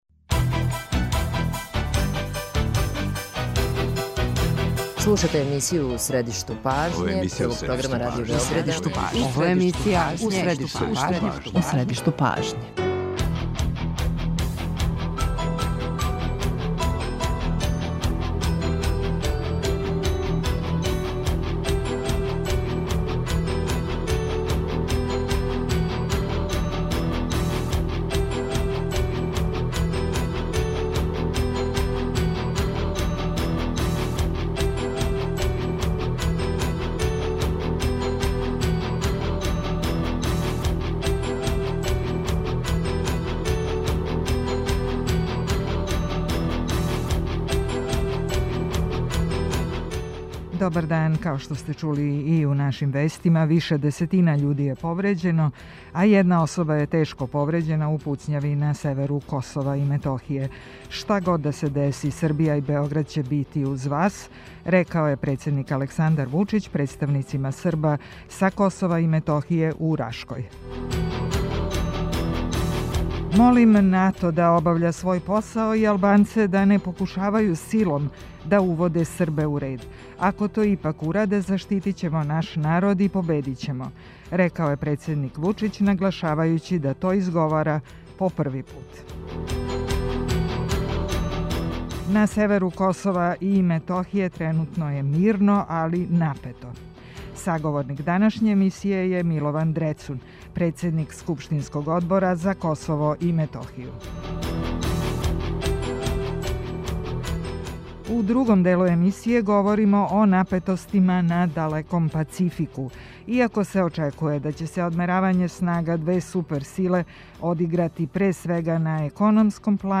Срби су камионима блокирали поједине градске улице и раскрснице. О најновијим збивањима у јужној покрајини за емисију У средишту пажње говори Милован Дрецун, председник Одбора Скупштине Србије за Косово и Метохију.